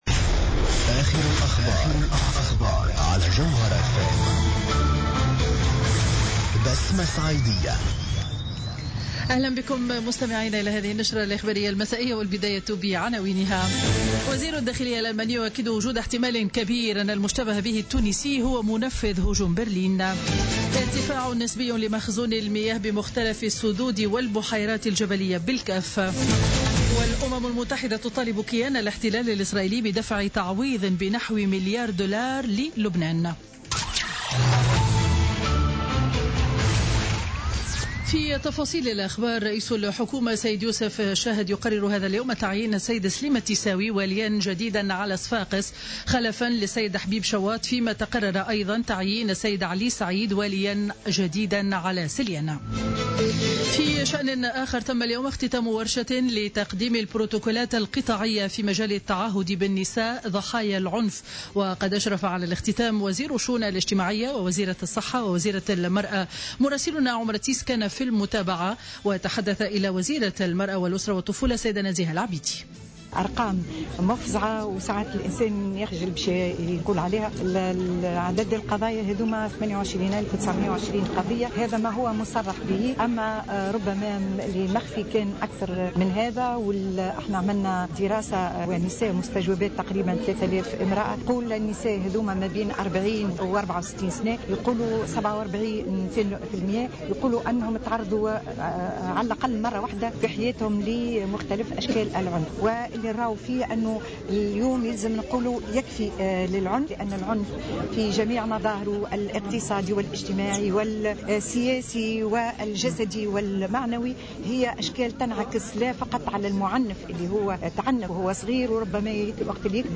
نشرة أخبار السابعة مساء ليوم الخميس 22 ديسمبر 2016